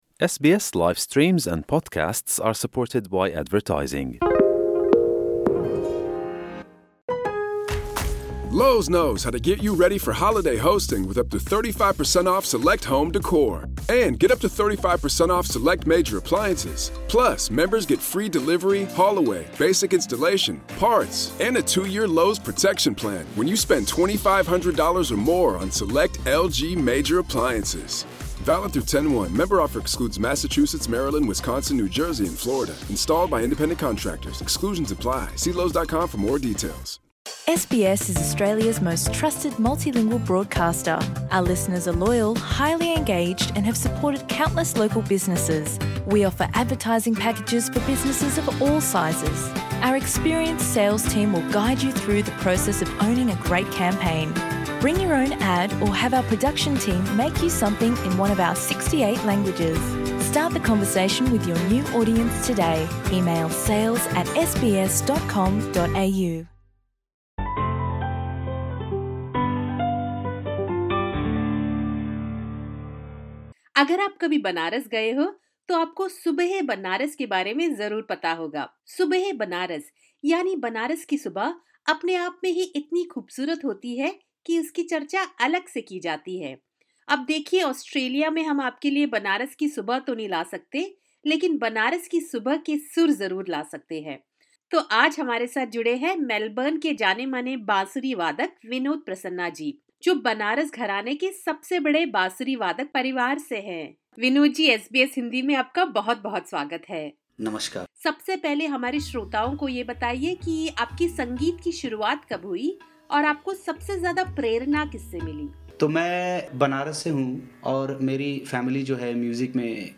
In a conversation with SBS Hindi